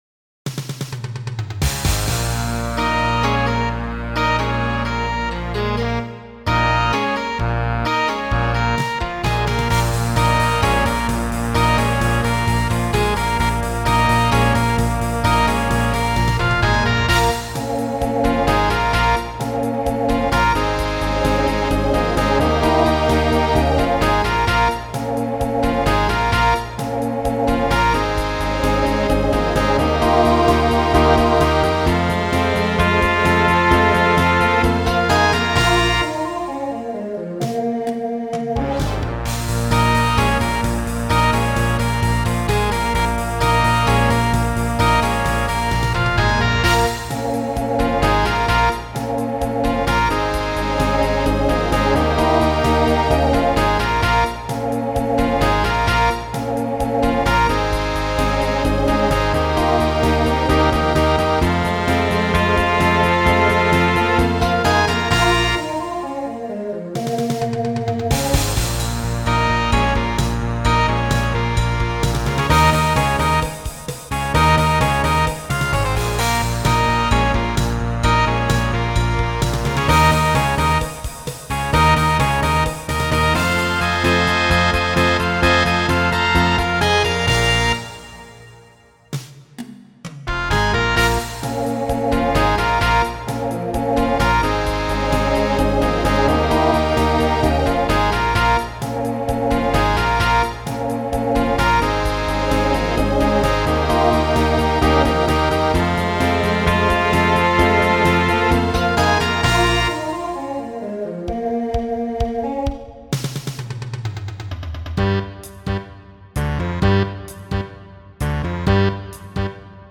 TTB/SSA
Voicing Mixed Instrumental combo Genre Rock